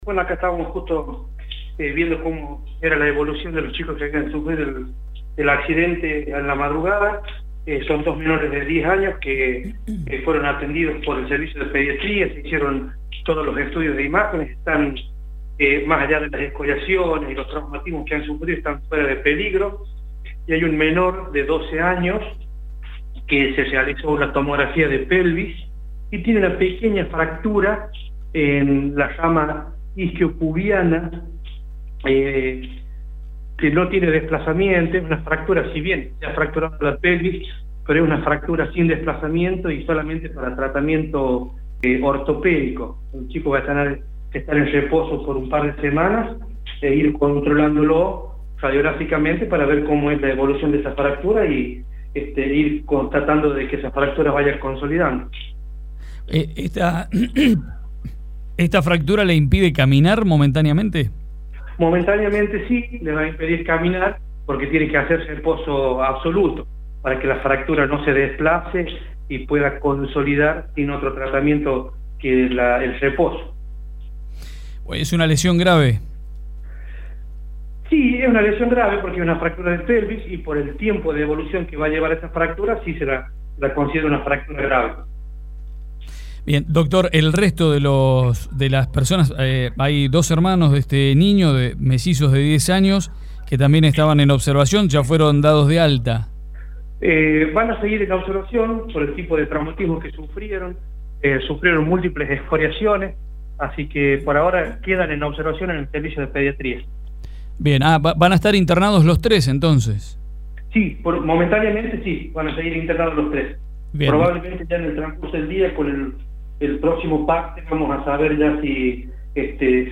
en una comunicación telefónica con el programa Las Cosas por el Aire